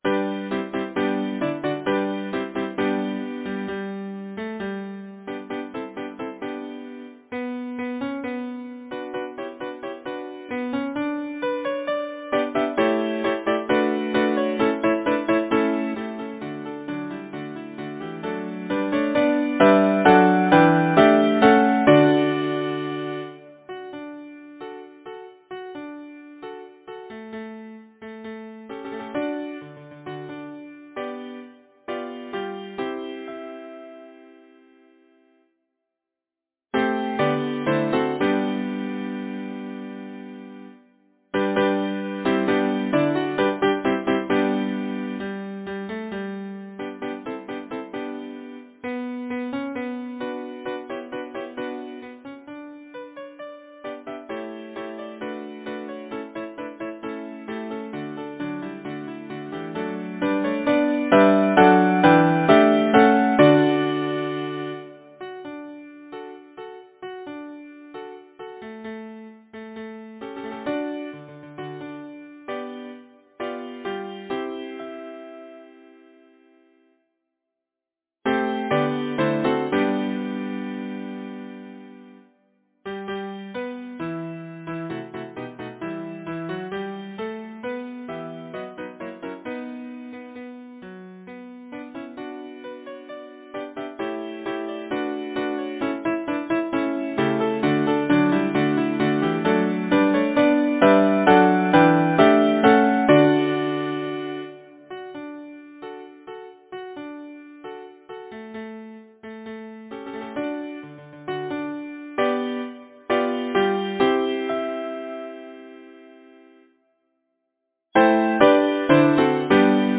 Title: Spring Composer: Frederic Hymen Cowen Lyricist: Thomas Nashe Number of voices: 4vv Voicing: SATB Genre: Secular, Partsong
Language: English Instruments: A cappella